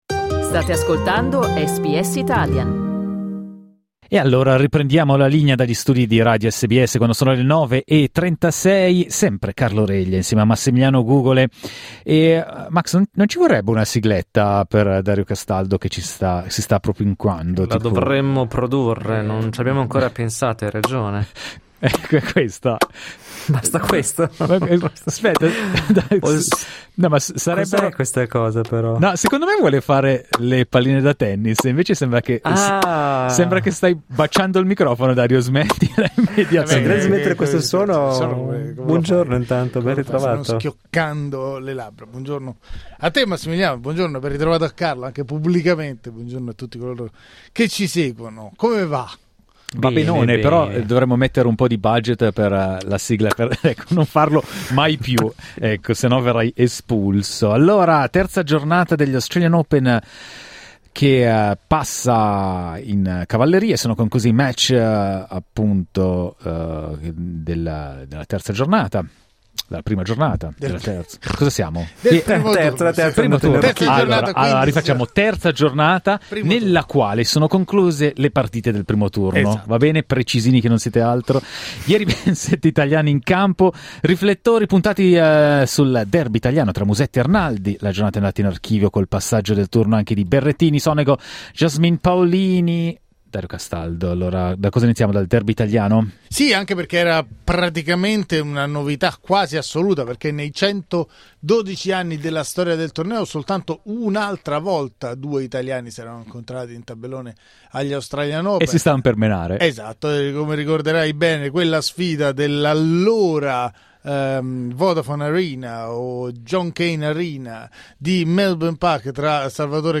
Ascolta il resoconto della giornata e le voci dei protagonisti cliccando sul tasto "play" in alto POTREBBE INTERESSARTI ANCHE: Quali follie fate per il tennis?